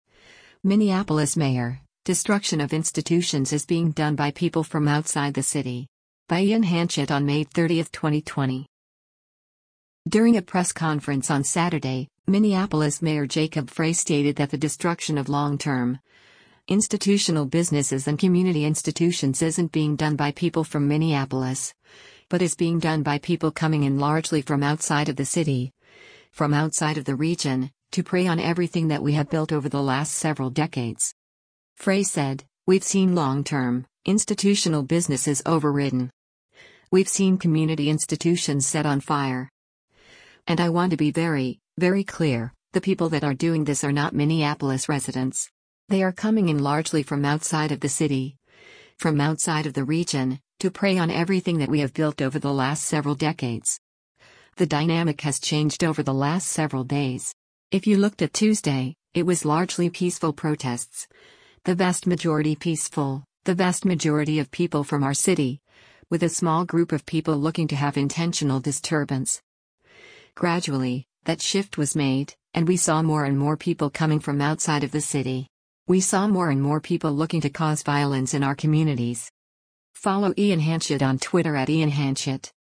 During a press conference on Saturday, Minneapolis Mayor Jacob Frey stated that the destruction of “long-term, institutional businesses” and “community institutions” isn’t being done by people from Minneapolis, but is being done by people “coming in largely from outside of the city, from outside of the region, to prey on everything that we have built over the last several decades.”